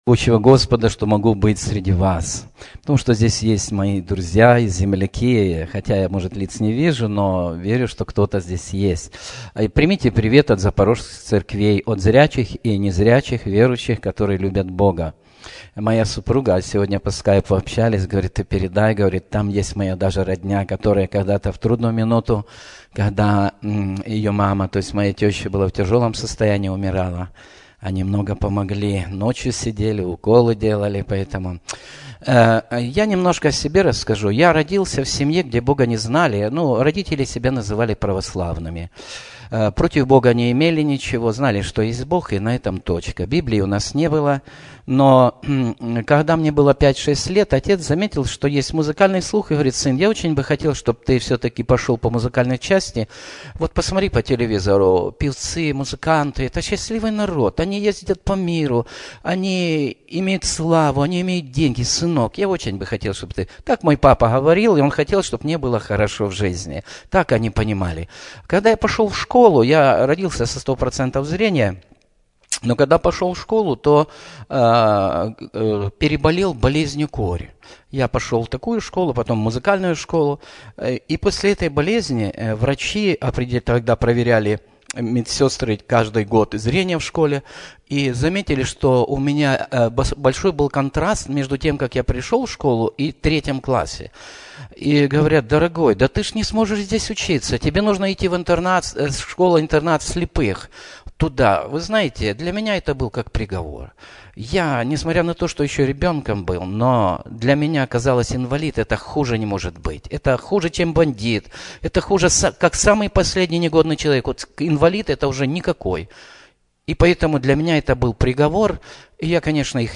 Все Проповеди